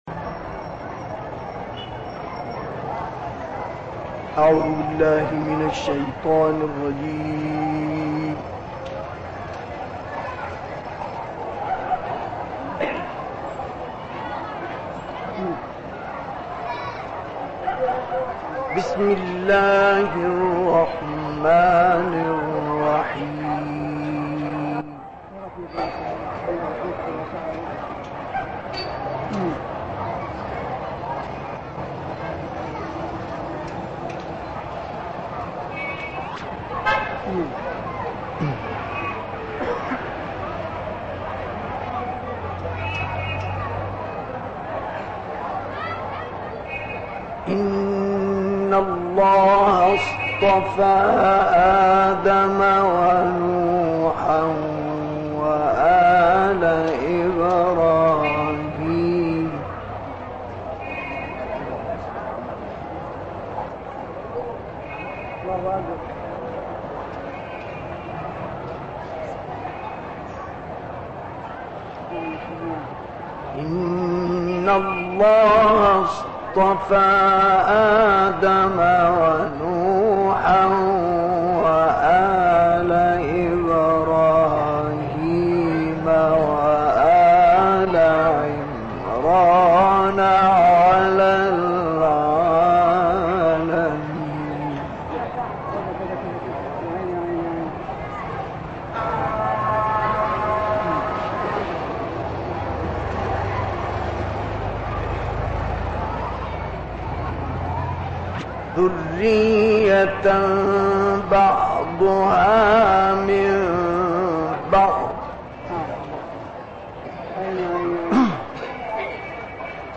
گروه فعالیت‌های قرآنی: تلاوت دلنشین مصطفی اسماعیل از آیات سوره مبارکه آل عمران ارائه می‌شود.
این تلاوت در سال 1971 میلادی در مسجد سیده عایشه شهر قاهره اجرا شده است و مدت زمان آن 55 دقیقه است.